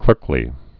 (klûrklē)